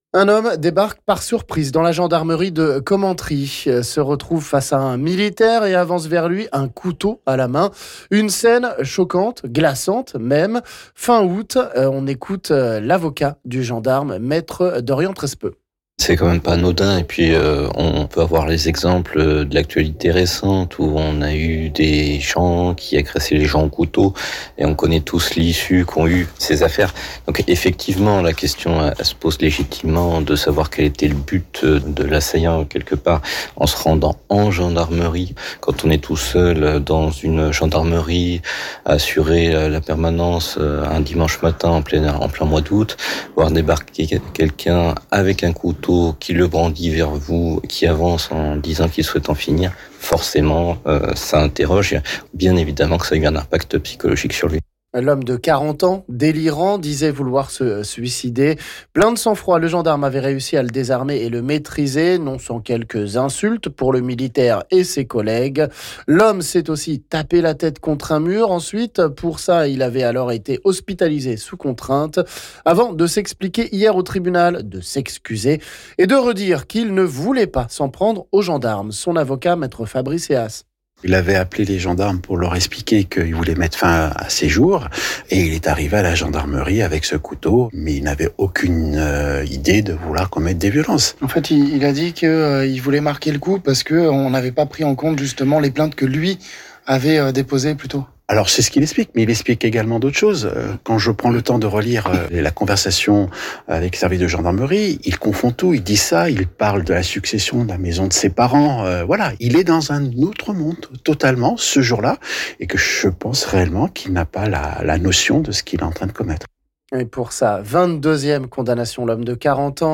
On écoute ici les avocats des 2 parties...